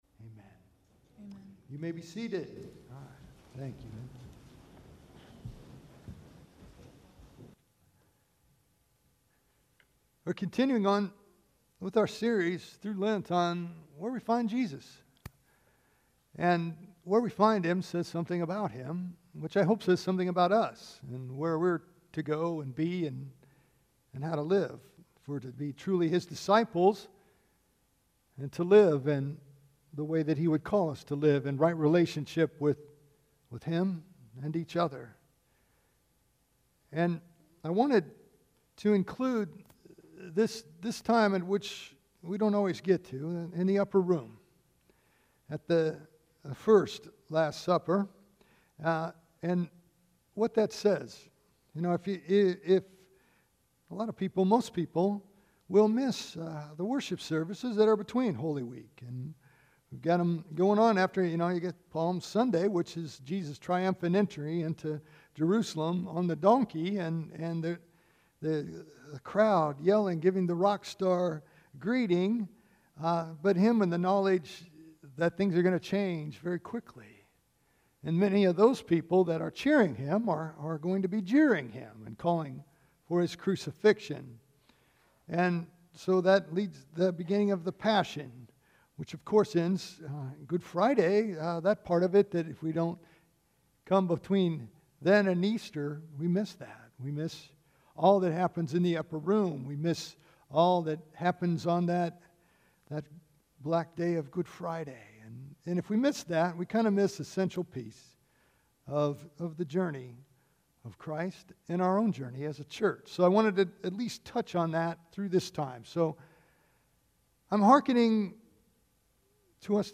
Fifth Sunday of Lent. In this season leading up to Easter, we learn to Love God, Love each Other, and Change the World.